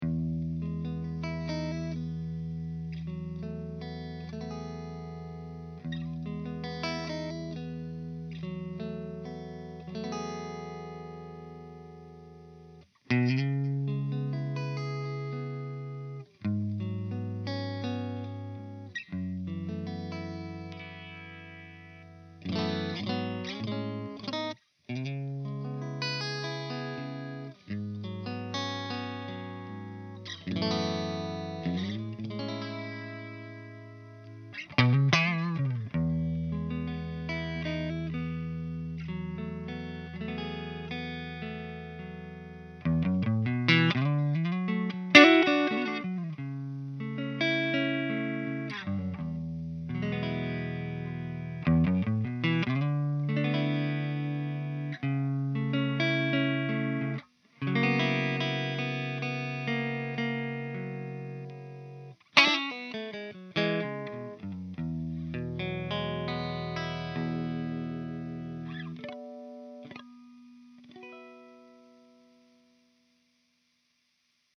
Clean riff 2